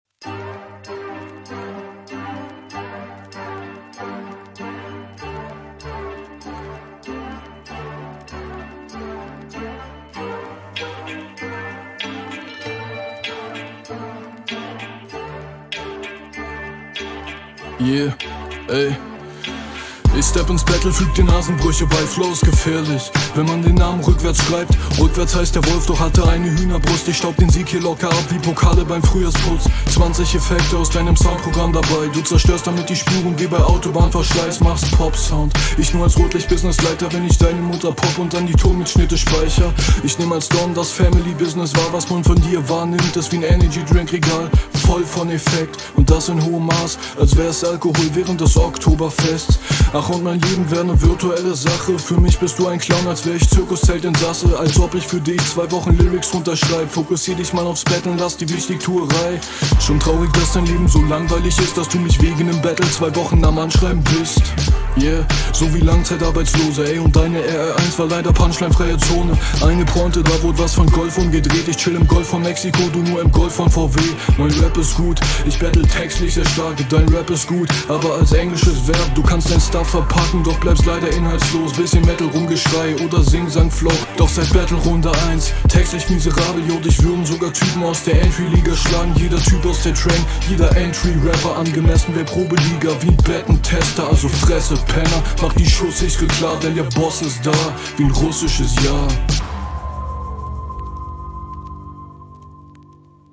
kommst auf den beat schonmal eindeutig schwächer. flow kommt nicht immer so flüssig und stimmeinsatz …